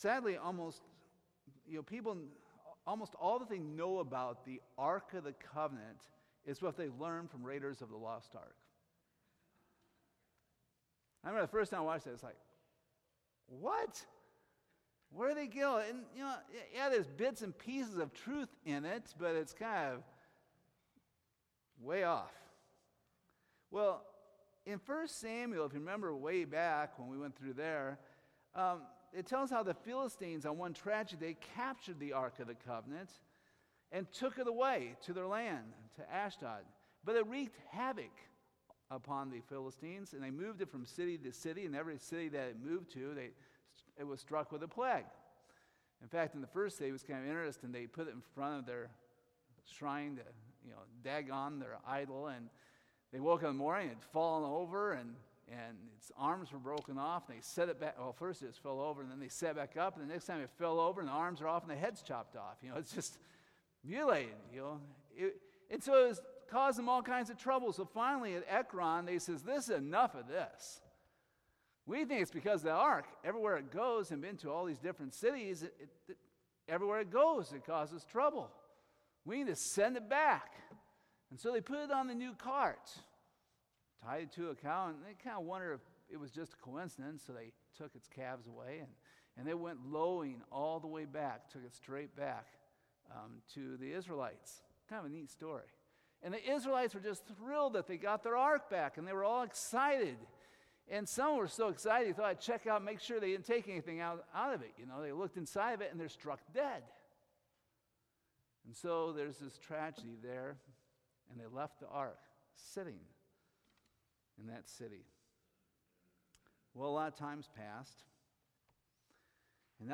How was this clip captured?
2 Samuel 6 Service Type: Sunday Evening Just how significant is the Ark of the Covenant?